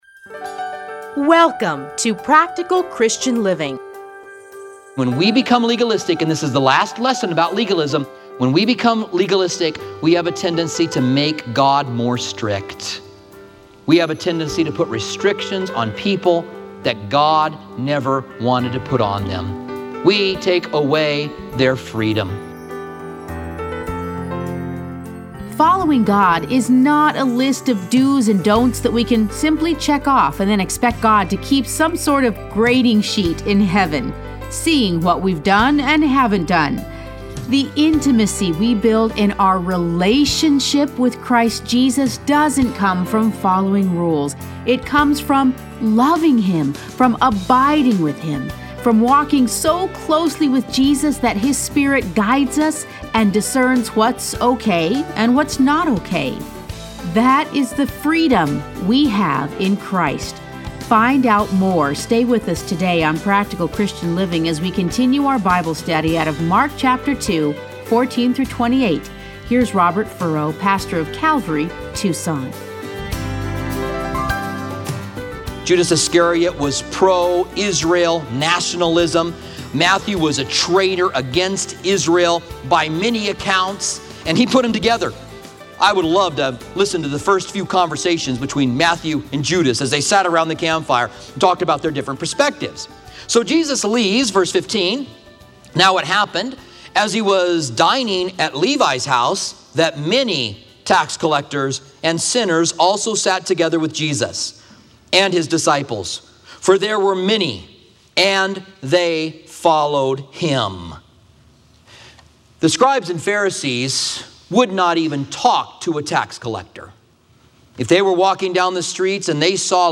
Listen to a teaching from Mark 2:14-28.